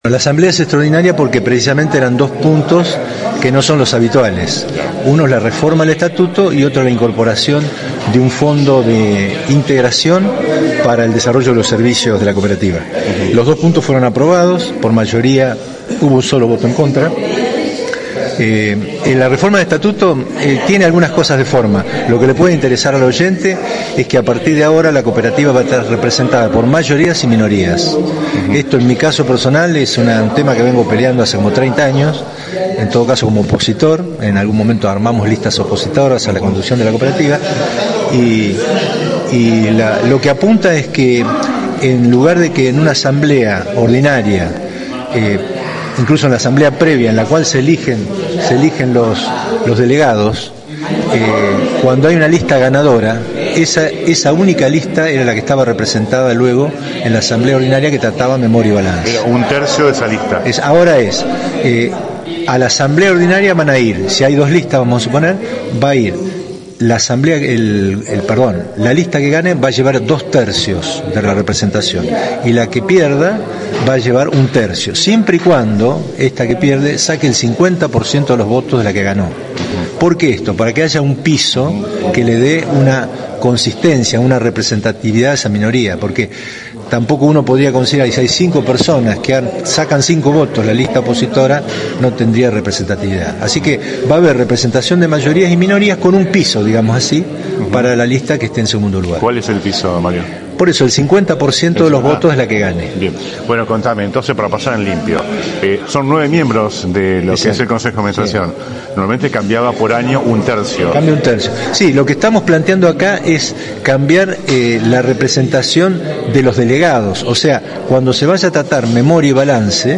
conversamos